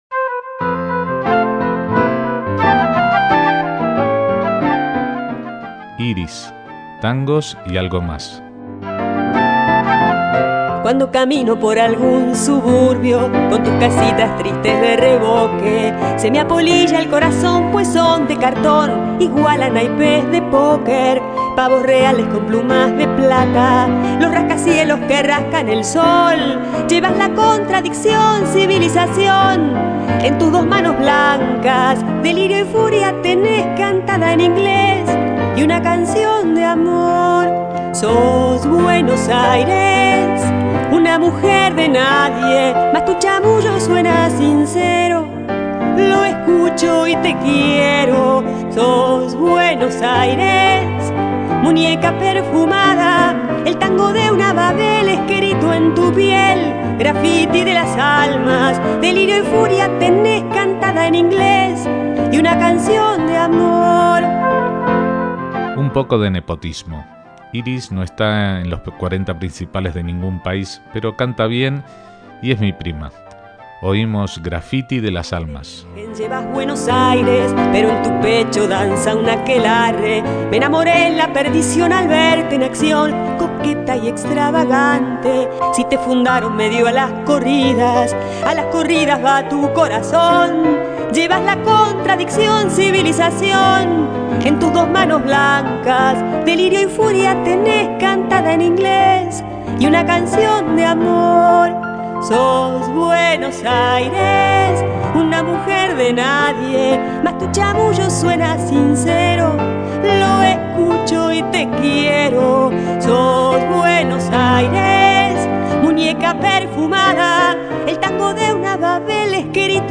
temas de tango actual